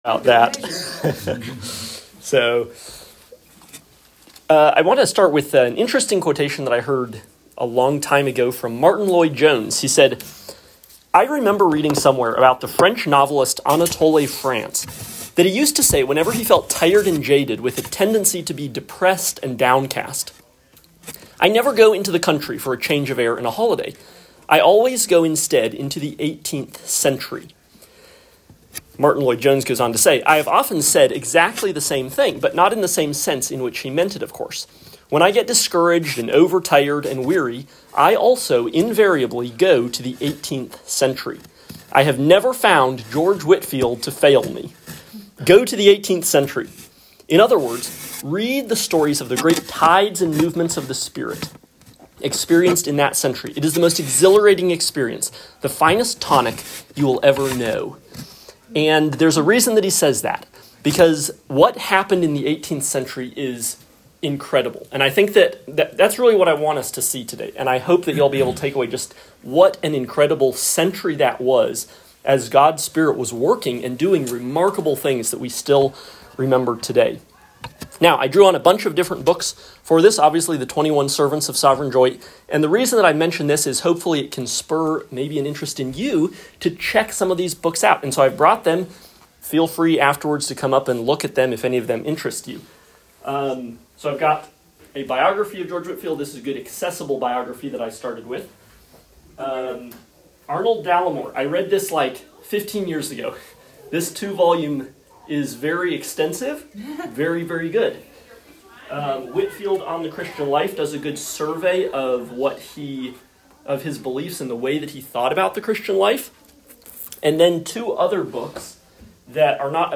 Lecture 4 – George Whitefield